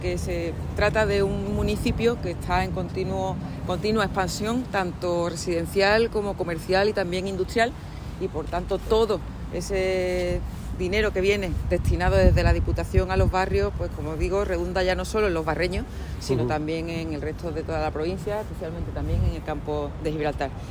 La presidenta de la Diputación, Almudena Martínez, el alcalde de Los Barrios, Miguel Alconchel; el vicepresidente tercero, Jacinto Muñoz; y miembros de la Corporación local han participado en la presentación oficial de los siete nuevos vehículos que ya están a disposición del Ayuntamiento -tres furgonetas de 5 plazas; dos de 3 plazas y otras dos de 9 plazas-.
Corte de Almudena Martínez